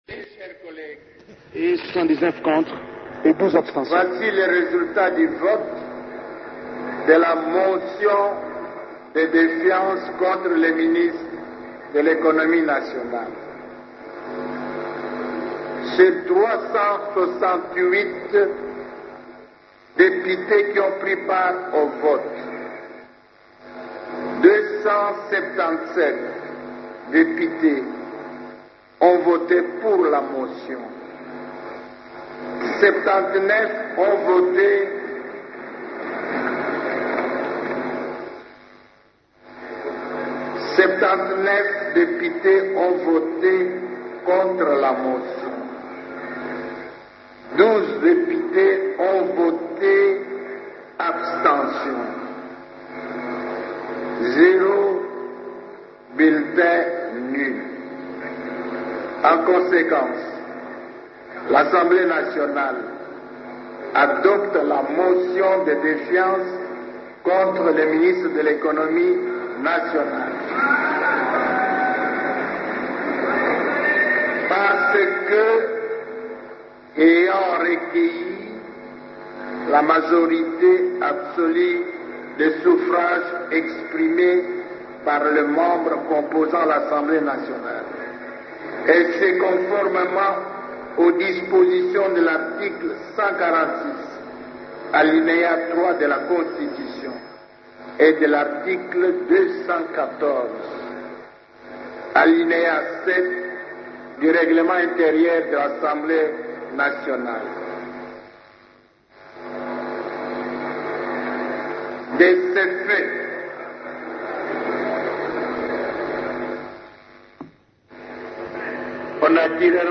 Dans cet extrait sonore, suivez le son de Christophe Mboso, président de l'Assemblée nationale, qui parle du résultat de vote: